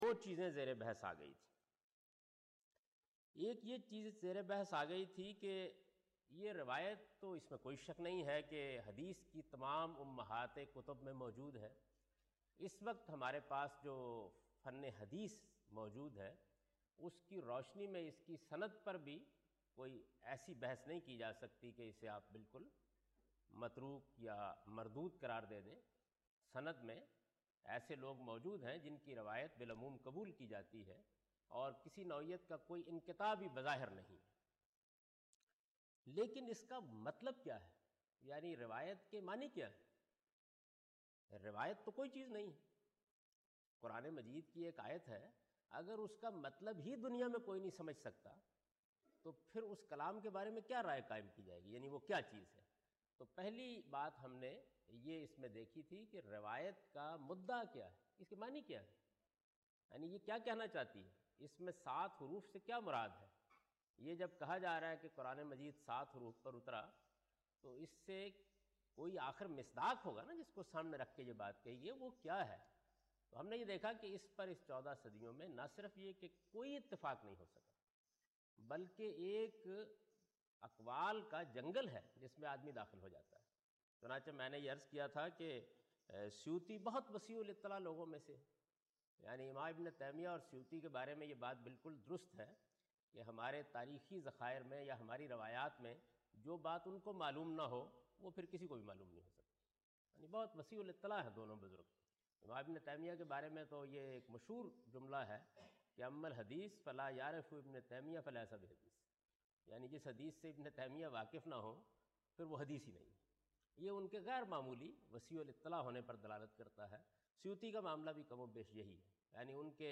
In this lecture he teaches the variant readings of Quran.